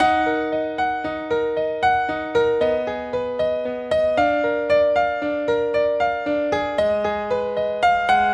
原声钢琴 115bpm D小调
描述：D小调的原声钢琴循环曲...
Tag: 115 bpm Hip Hop Loops Piano Loops 1.40 MB wav Key : D